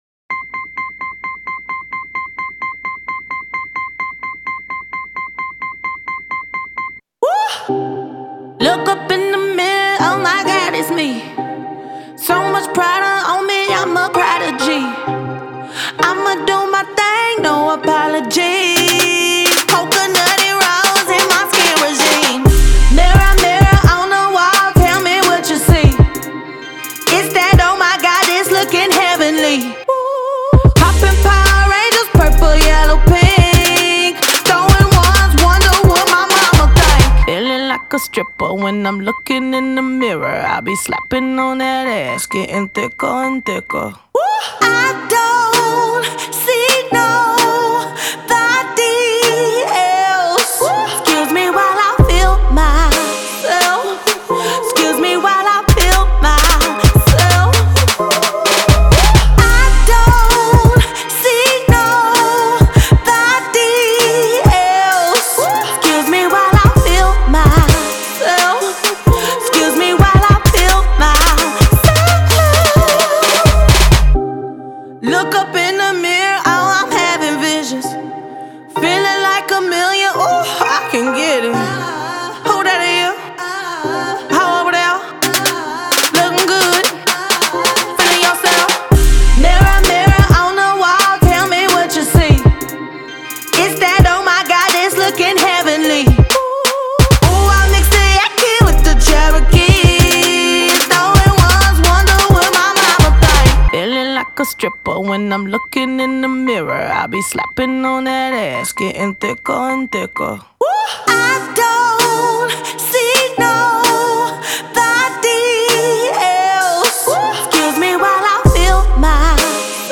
장르: Hip Hop, Funk / Soul
스타일: Contemporary R&B, Pop Rap